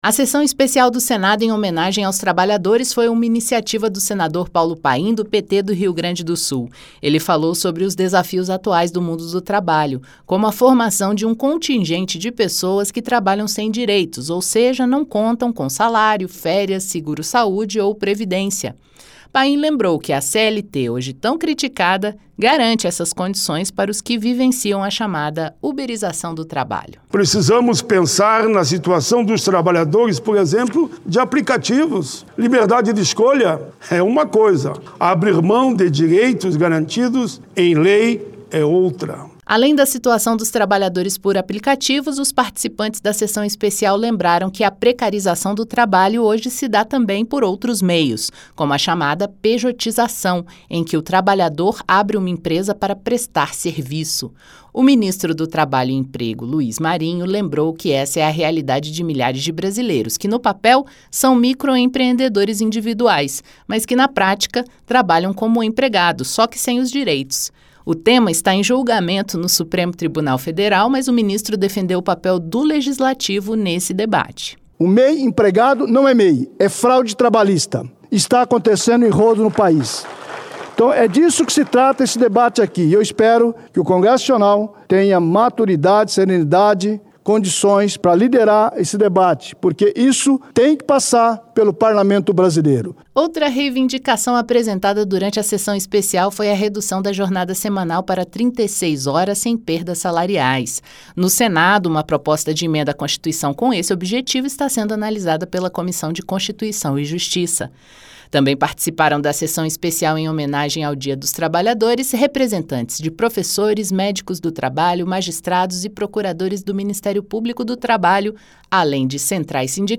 Sessão Especial